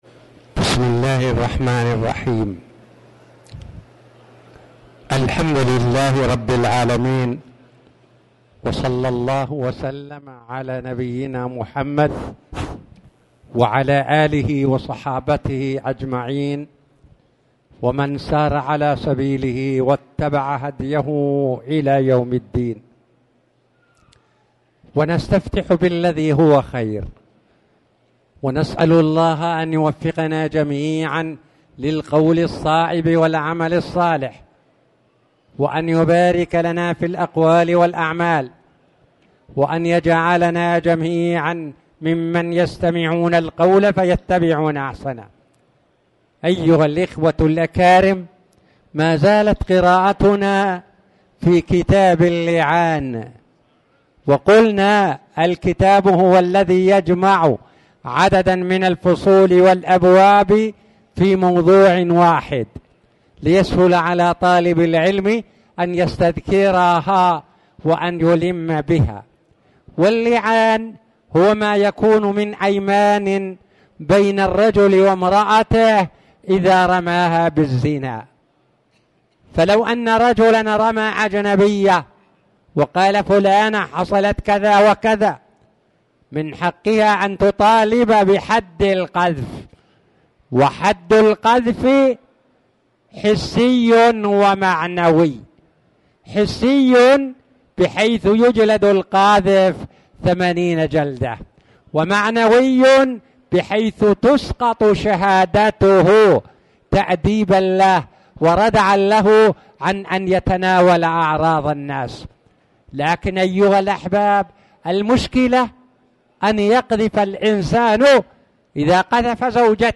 تاريخ النشر ١٧ شوال ١٤٣٨ هـ المكان: المسجد الحرام الشيخ